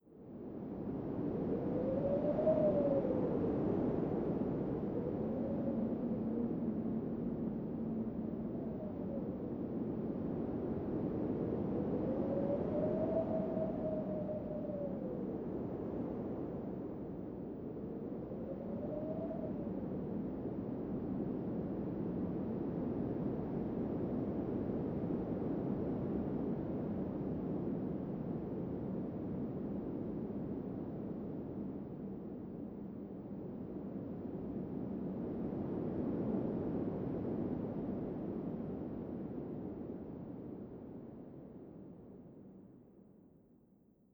wind2.wav